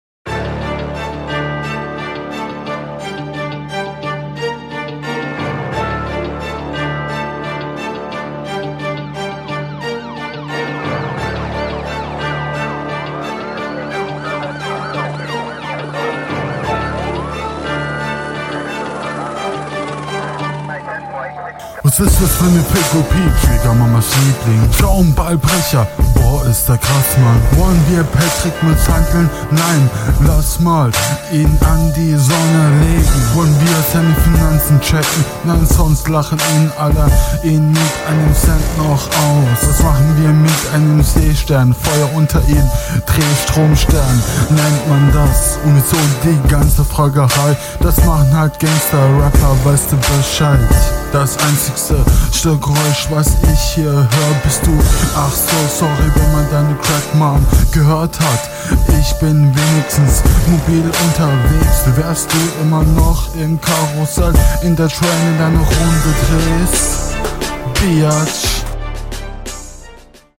Es klingt so als würdest du den Mund nicht wirklich aufmachen.